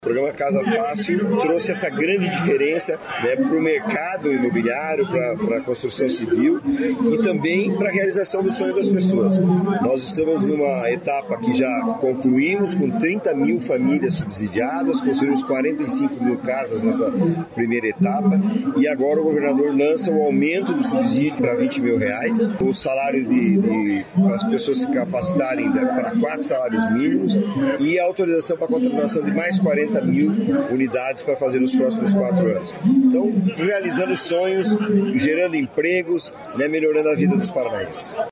Sonora do presidente da Cohapar, Jorge Lange, sobre a entrega de casas para 468 famílias de Arapongas